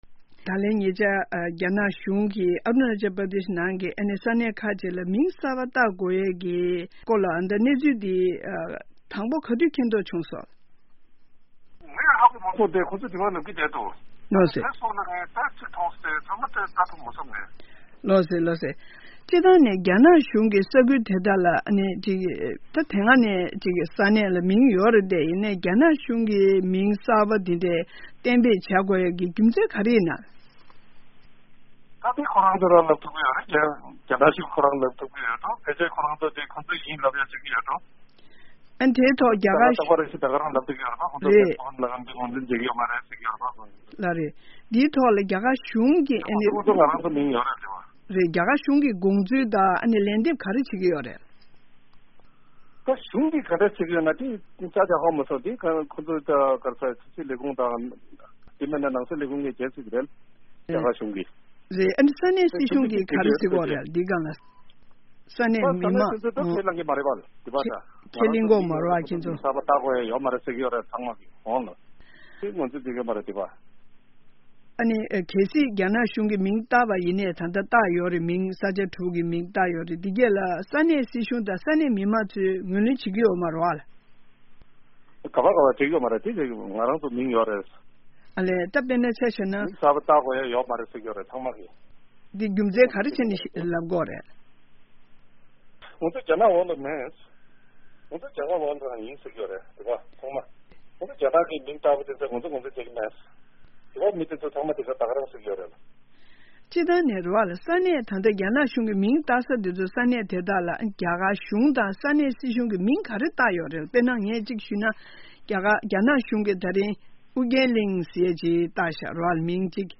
རྒྱ་ནག་གཞུང་གིས་རྒྱ་གར་ཤར་ཕྱོགས་མངའ་སྡེ་ཨ་རུ་ན་ཅལ་ནང་ཡོད་ མོན་རྟ་དབང་གི་ས་ཁུལ་དེ་དག་བོད་ཀྱི་ལྷོ་ཕྱོགས་ས་མཚམས་ཡིན་པར་ངོས་འཛིན་བྱས་ཏེ་བདག་དབང་རྩོད་གླེང་བྱེད་ཀྱི་ཡོད་པ་ལྟར། ད་རེས་སྤྱི་ཟླ་༤ཚེས་༡༣ཉིན་རྒྱ་ནག་གཞུང་འབྲེལ་གྱི་དྲ་རྒྱའི་སྟེང་རྒྱ་གར་གྱི་ཤར་ཕྱོགས་མོན་རྟ་དབང་ཁུལ་གྱི་ས་གནས་ཁག་དྲུག་ལ་མིང་གསར་པ་གཏན་འབེབས་བྱས་པ་ཁག་རྒྱ་སྐད་དང་བོད་སྐད། རོ་མཎ་སྐད་ཡིག་ཐོག་གསལ་སྟོན་བྱས་ཡོད་པའི་གནས་ཚུལ་དེའི་ཐད་མོན་རྟ་དབང་གྲོང་ཁྱེར་གྱི་རྫོང་དཔོན་སངས་རྒྱས་ཕུན་ཚོགས་ལགས་སུ་གནས་འདྲི་ཞུས་པ་དེ་གསན་རོགས་གནང་།